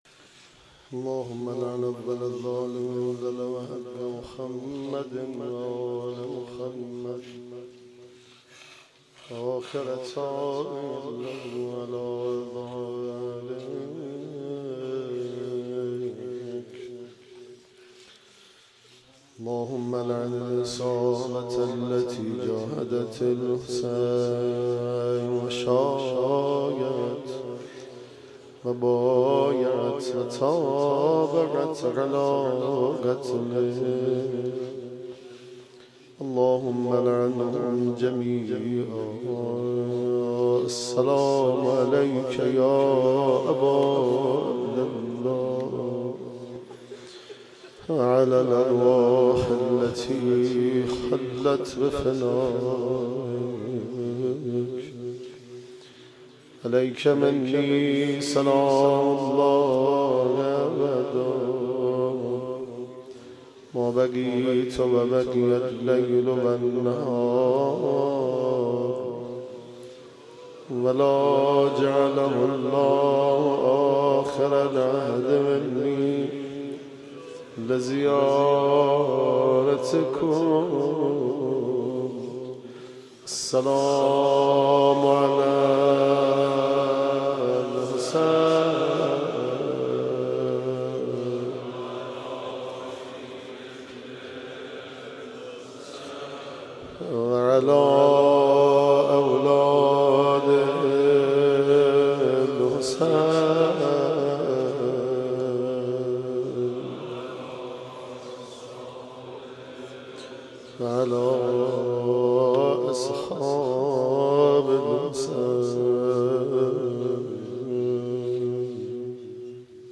مرثیه سرایی
روضه